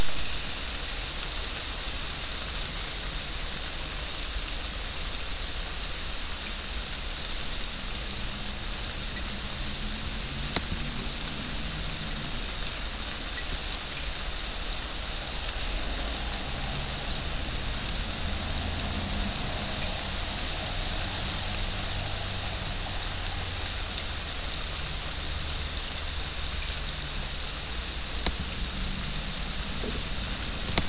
Raining
It’s raining hard out here tonight, so I recorded some awkward laptop mic audio of it to share. It’s mostly just rain hitting the leaves of the trees outside.